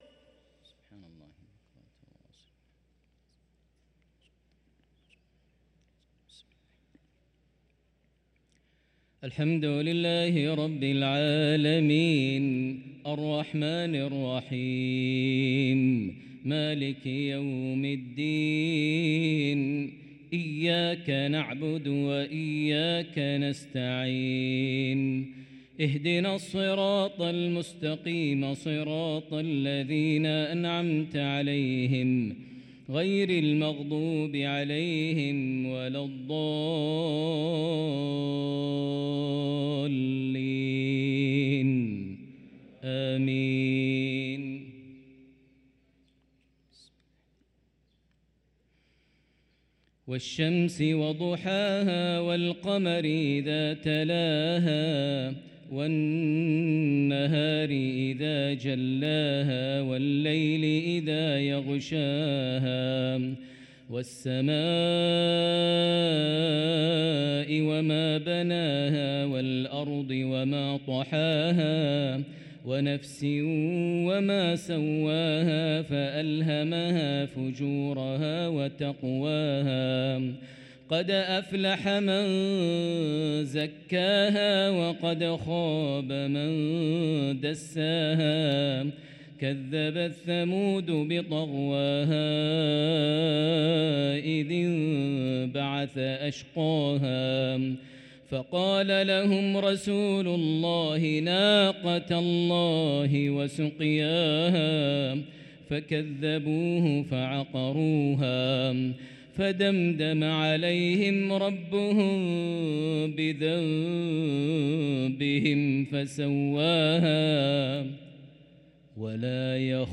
صلاة المغرب للقارئ ماهر المعيقلي 20 ربيع الأول 1445 هـ
تِلَاوَات الْحَرَمَيْن .